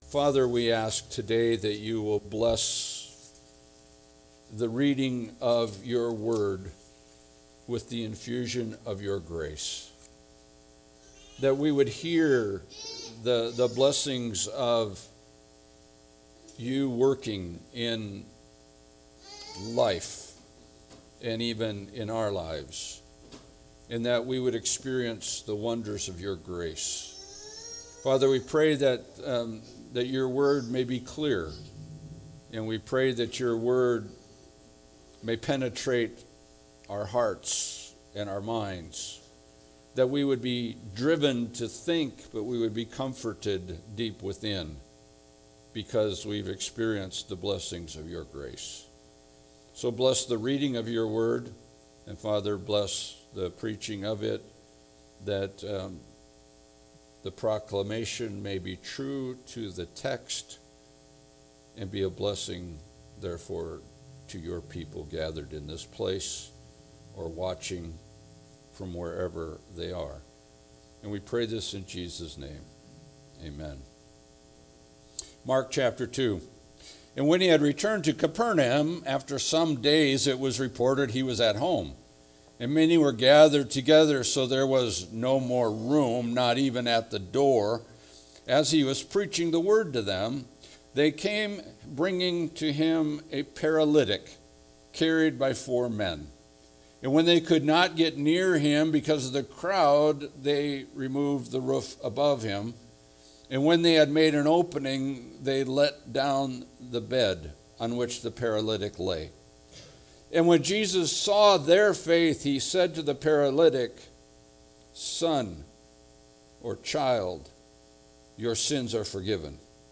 Mark 2:1-22 Service Type: Sunday Service « The Gospel for the Unlovable How Will You Manifest Yourself?